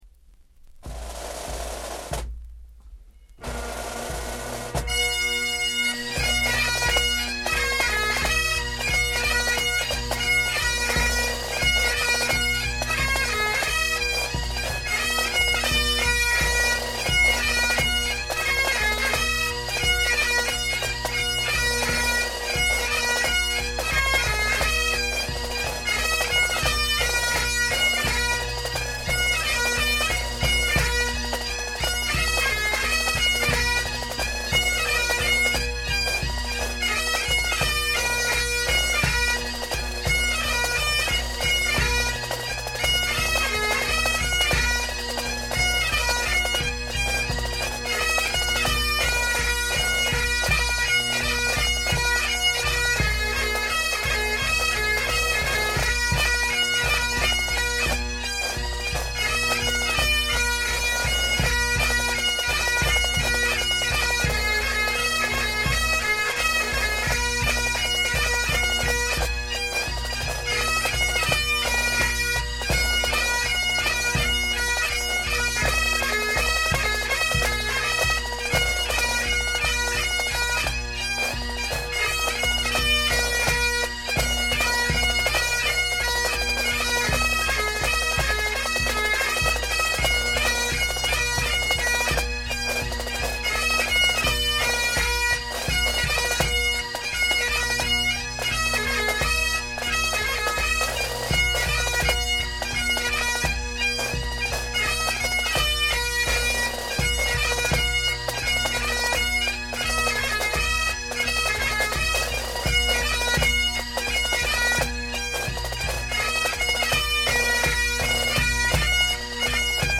ClanMacFarlaneMSR1978.wav